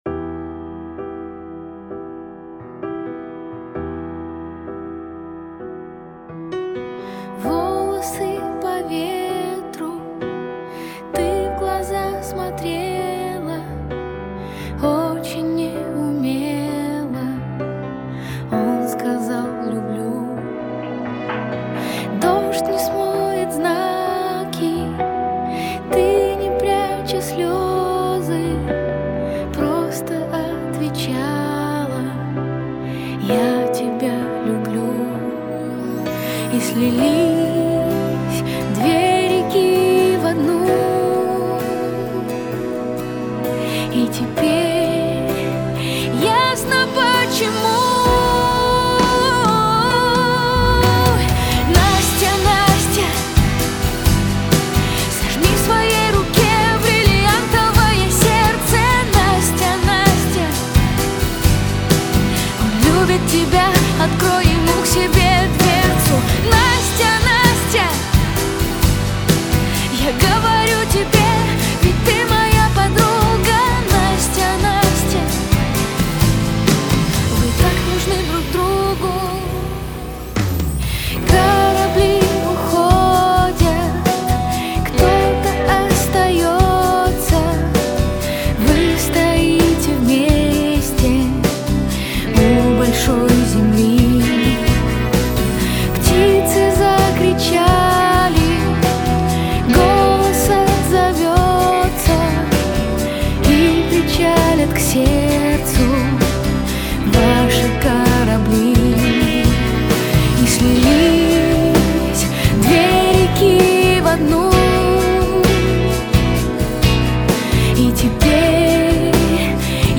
• Жанр: Русские песни